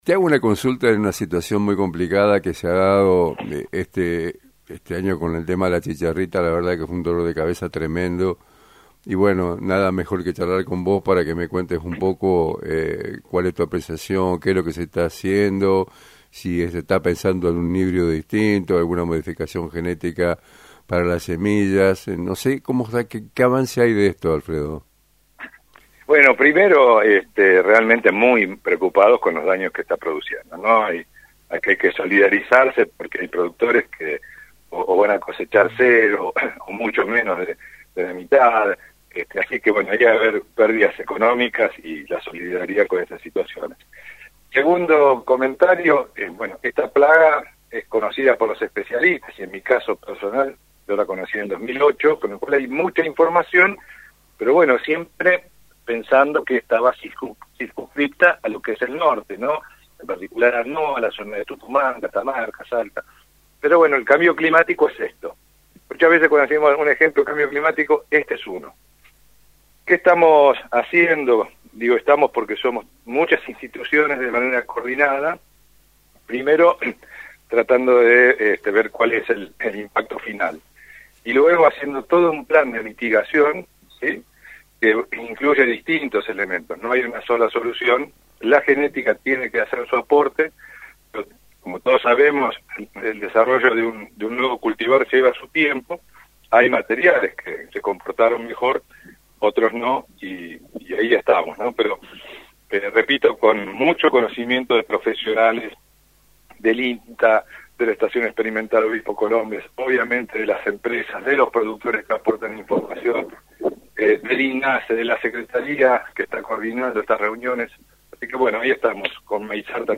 Avances y desafíos en la lucha contra la chicharrita: Entrevista